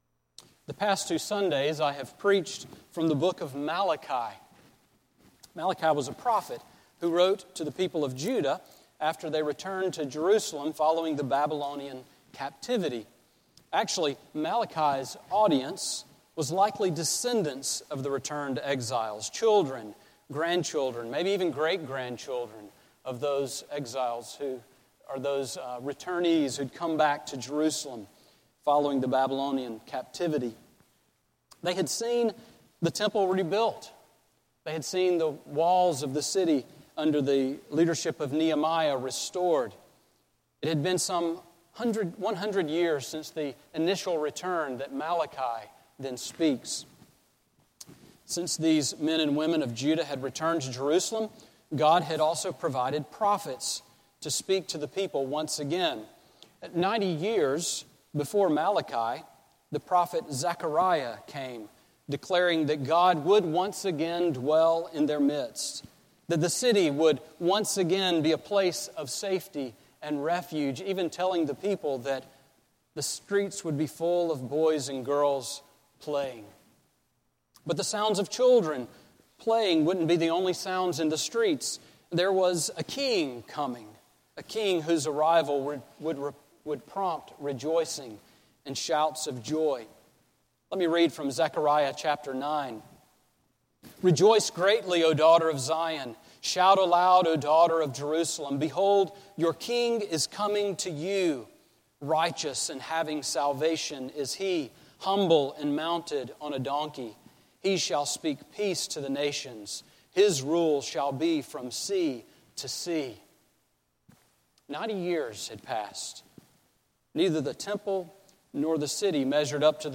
Sermon on Malachi 2:10-16 from November 16